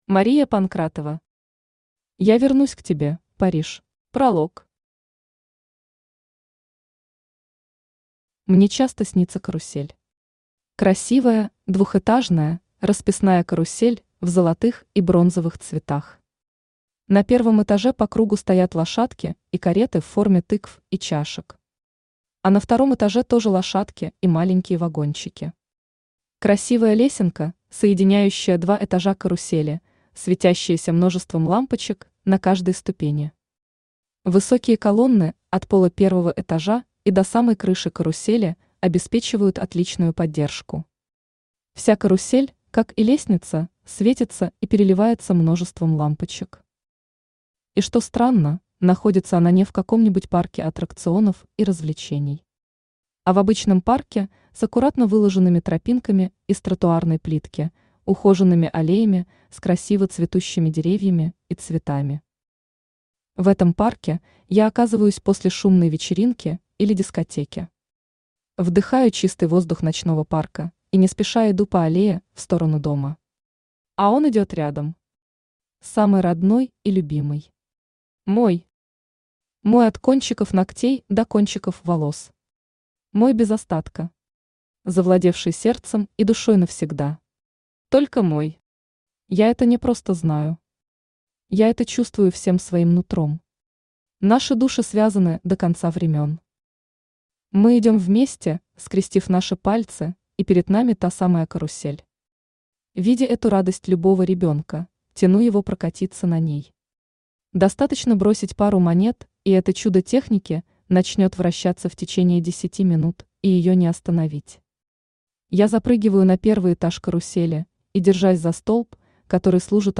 Аудиокнига Я вернусь к тебе, Париж | Библиотека аудиокниг
Aудиокнига Я вернусь к тебе, Париж Автор Мария Панкратова Читает аудиокнигу Авточтец ЛитРес.